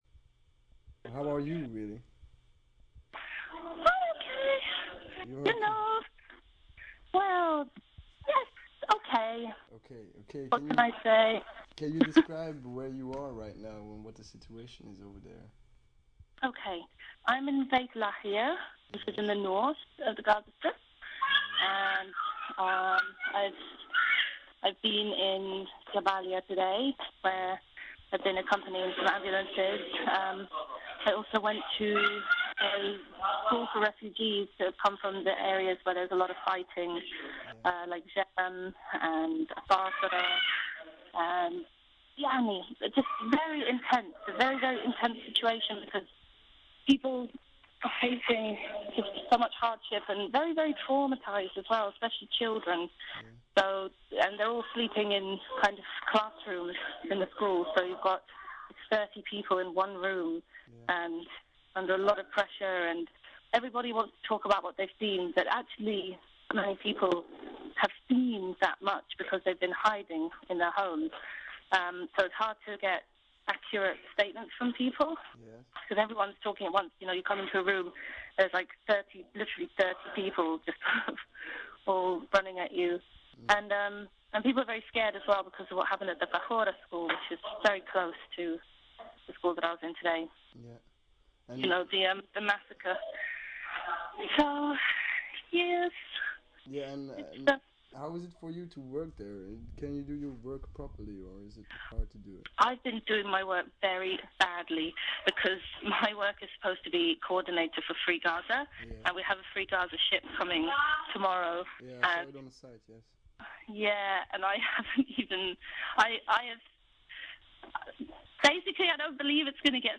From Gaza: interview
FreeGazaInterview.mp3